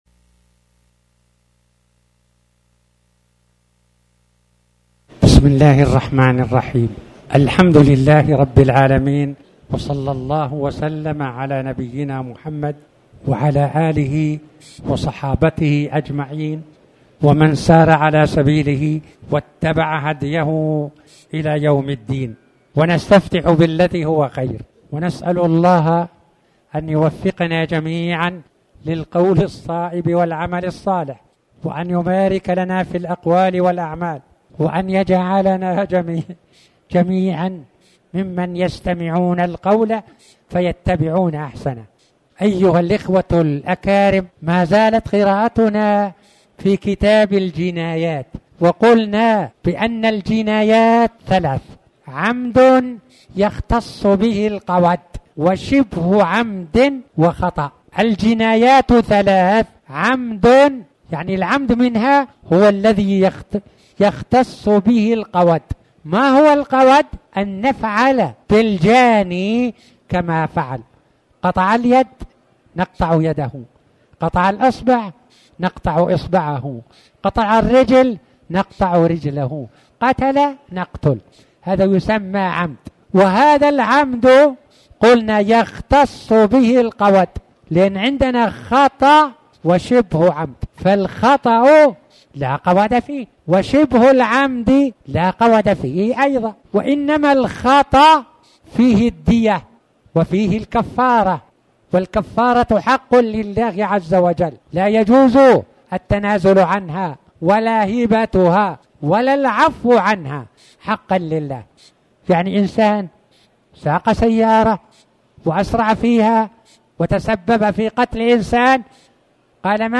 تاريخ النشر ١٥ رجب ١٤٣٩ هـ المكان: المسجد الحرام الشيخ